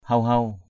/hau-hau/ (t.) xanh xanh, mơn mởn. padai huw-huw p=d h~|-h~| lúa tươi xanh. phun amil coh hala huw-huw f~N am{L _cH hl% h~|-h~| cây me đâm lá xanh xanh.